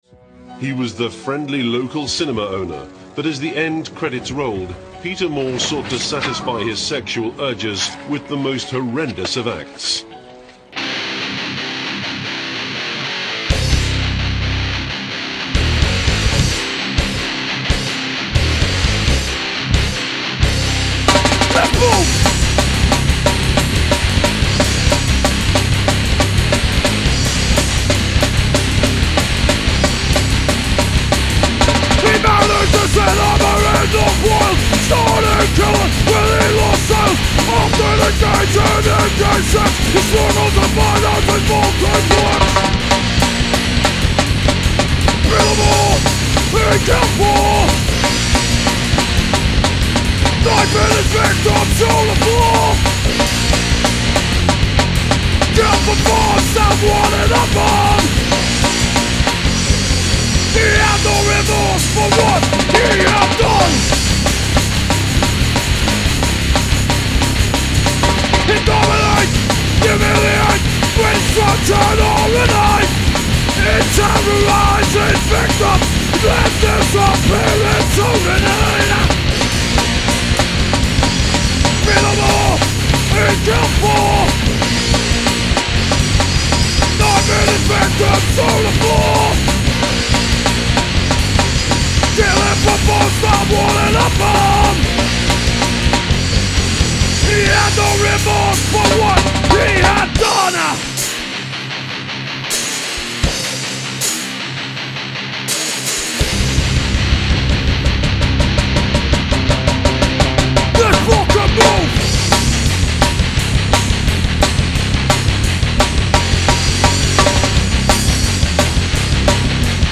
chugging D-beat riff